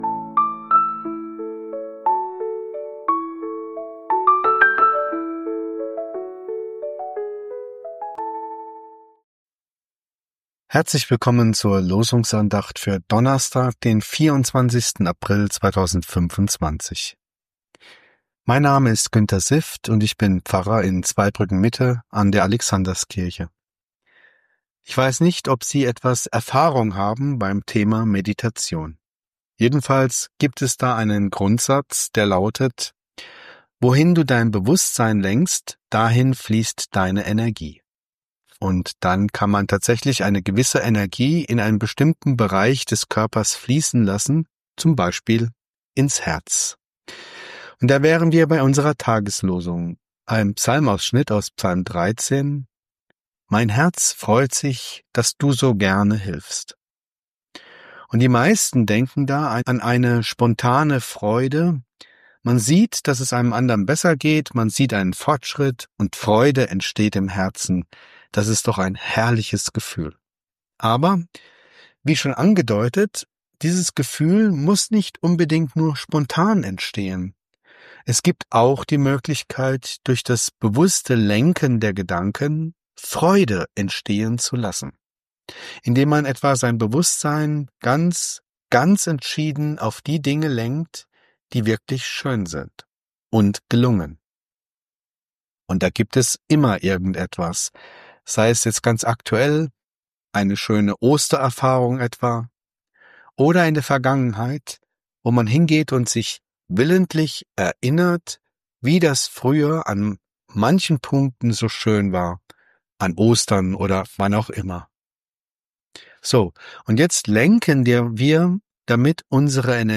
Losungsandacht für Donnerstag, 24.04.2025